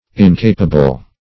Incapable \In*ca"pa*ble\, n.